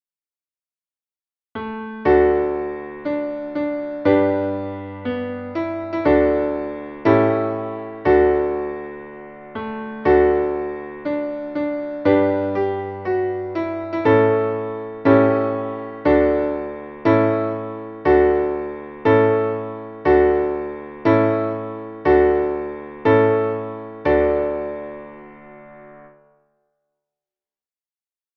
Traditionelles Trink-/ Feierlied